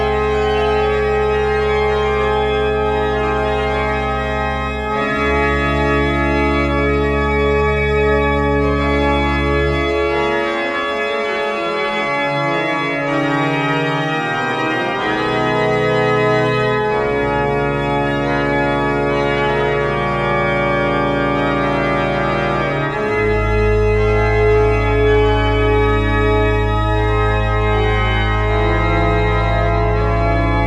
0 => "Orgue"
0 => "Musique classique"